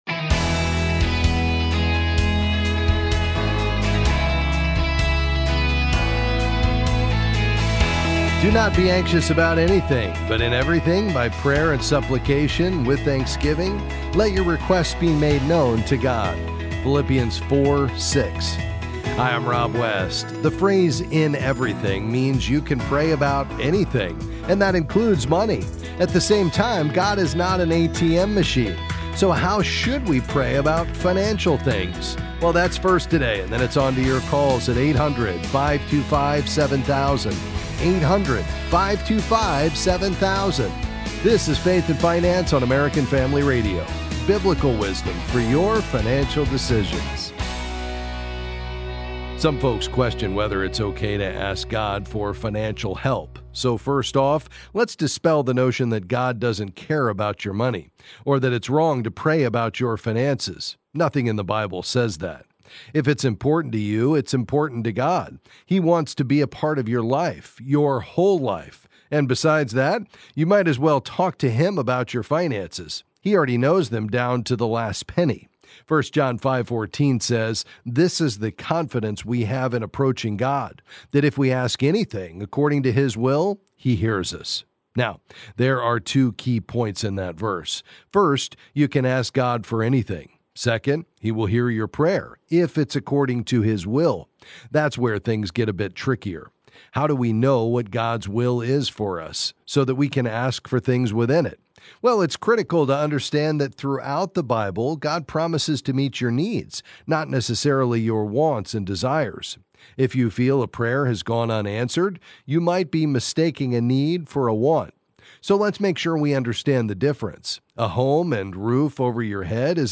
Then he’ll answer your questions on various financial topics.